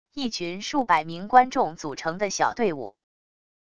一群数百名观众组成的小队伍wav音频